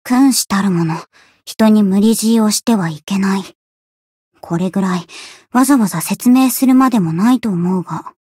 灵魂潮汐-青黛-互动-不耐烦的反馈2.ogg